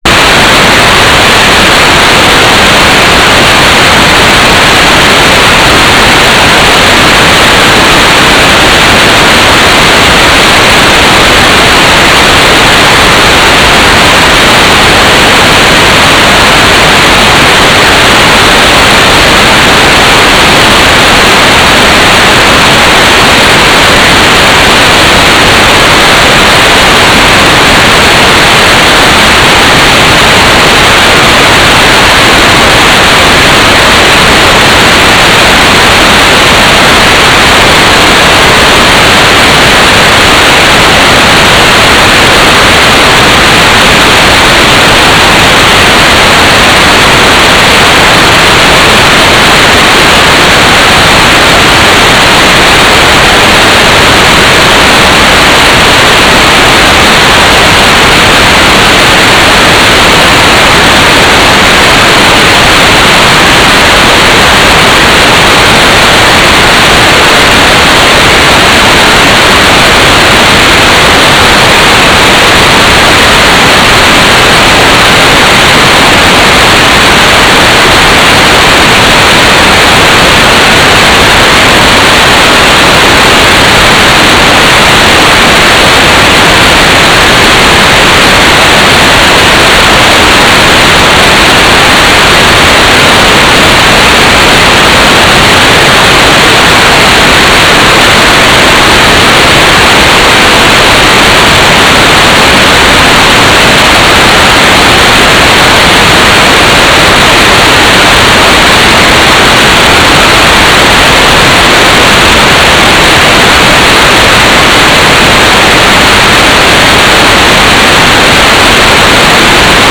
"transmitter_description": "Mode U - GFSK4k8 - AX.25 - Telemetry",